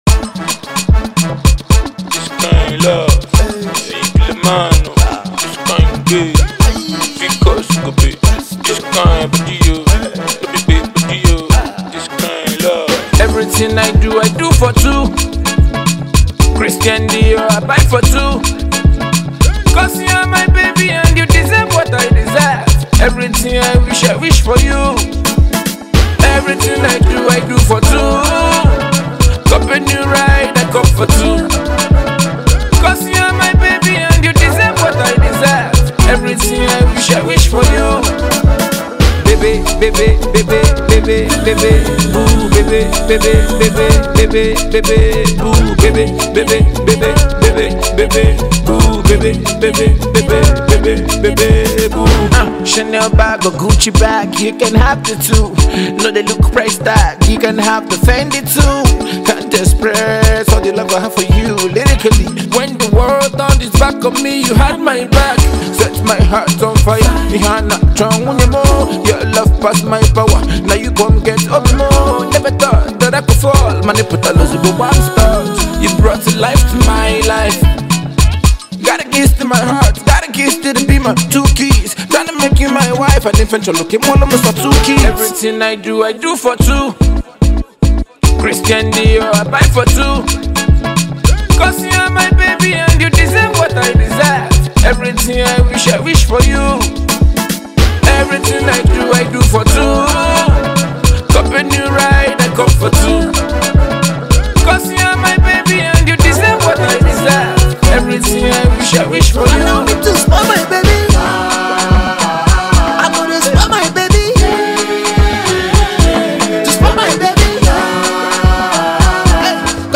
Nigerian Indigenous rapper
groovy instrumental